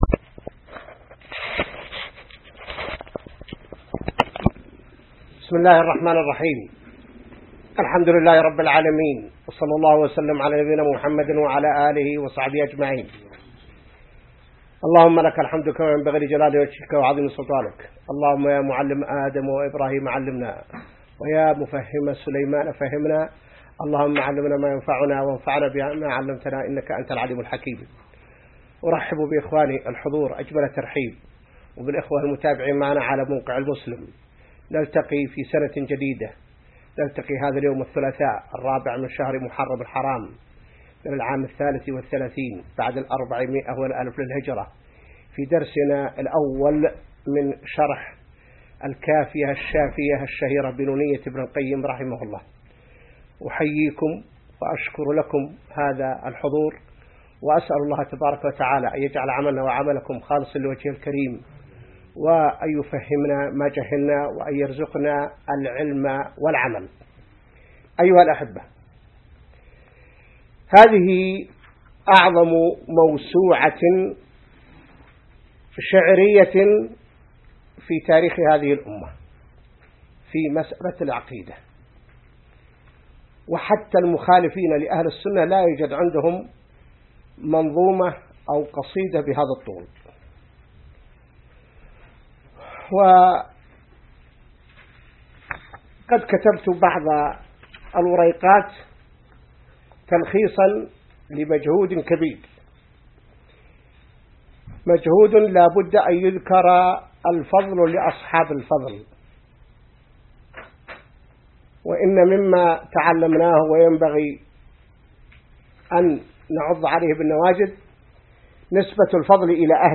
الدرس الأول من شرح نونية ابن القيم | موقع المسلم